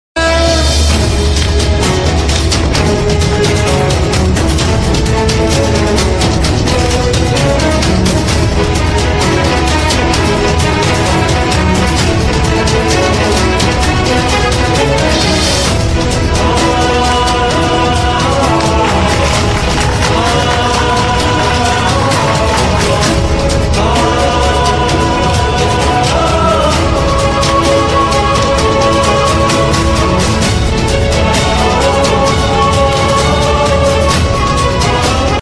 TV Serial Tone